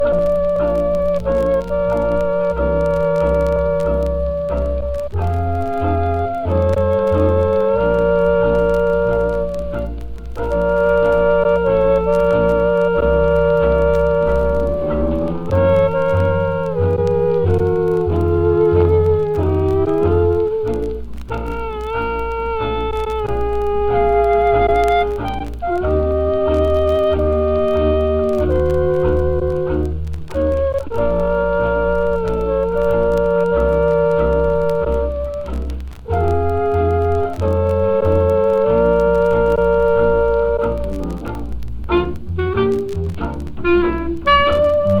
Jazz, Pop　USA　12inchレコード　33rpm　Mono
ジャケ汚れ　盤良好レーベル部シール貼付有　見開きジャケ　元音源に起因するノイズ有